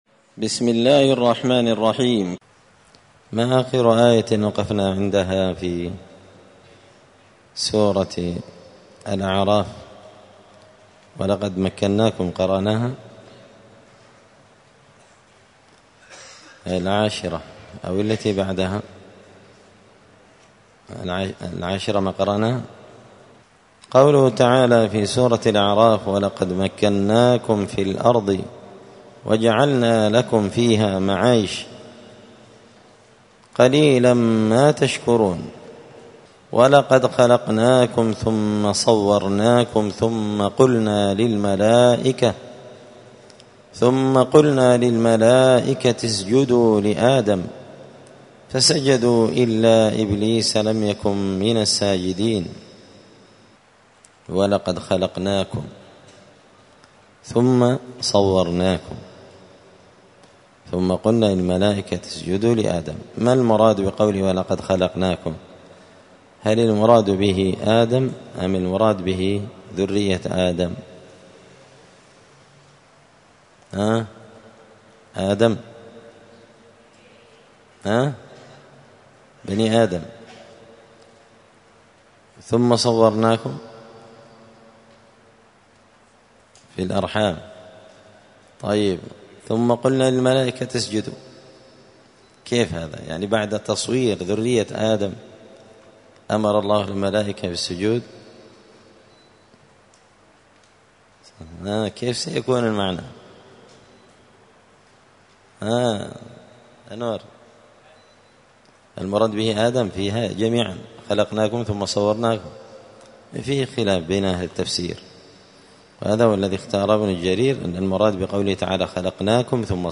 📌الدروس اليومية
دار الحديث السلفية بمسجد الفرقان بقشن المهرة اليمن